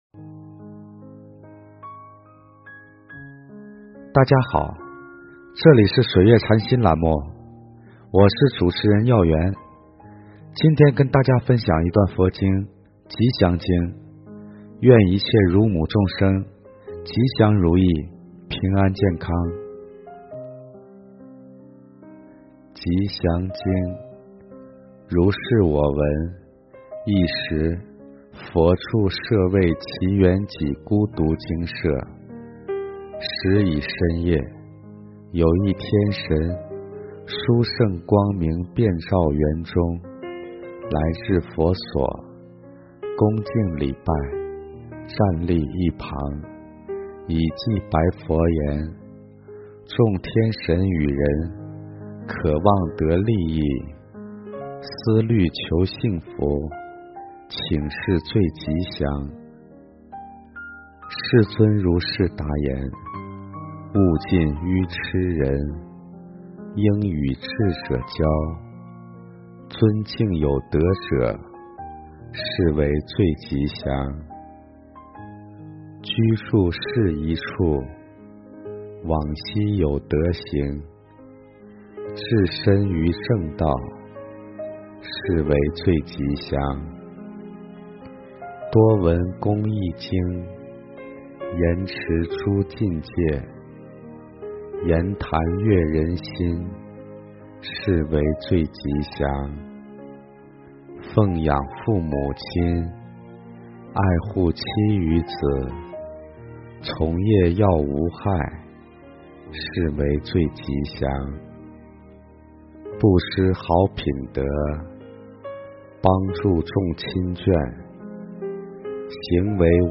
吉祥经（读诵）